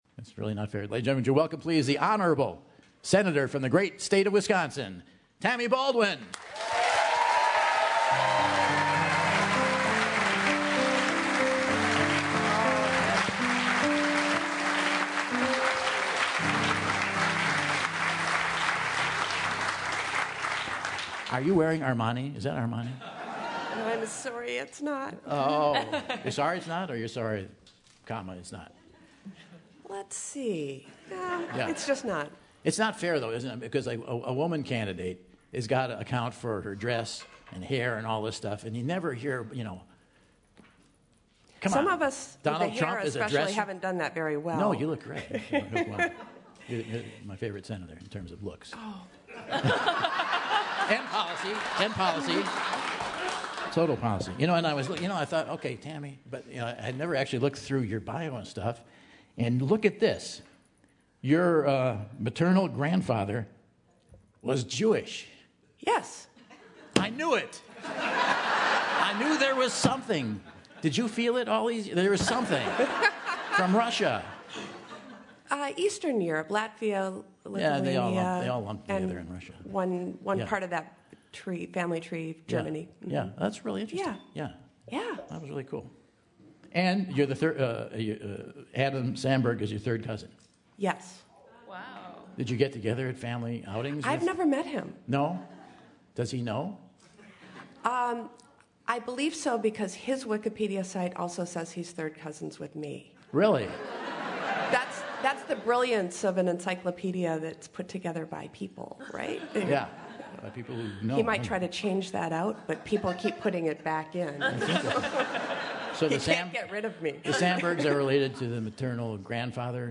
US Senator Tammy Baldwin returns to the Whad'Ya Know? stage for one last time giving Michael insight into the political world and more!